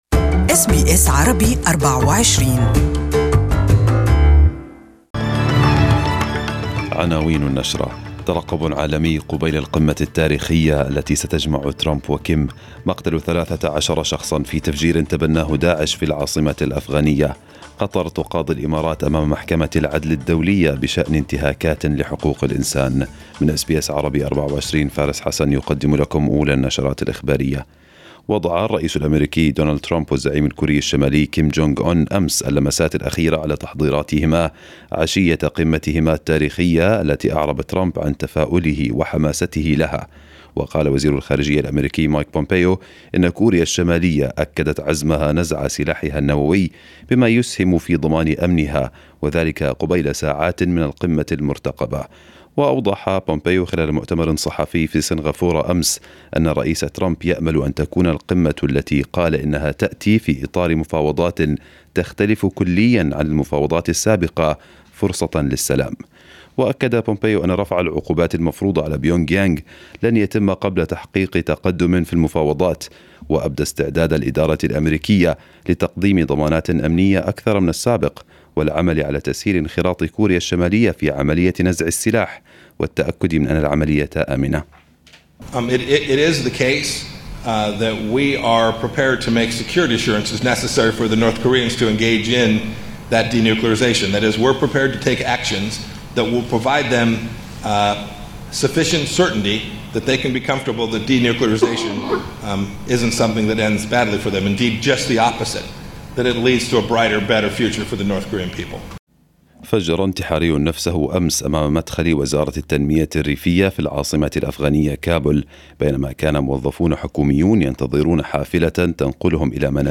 Arabic News Bulletin 12/06/2018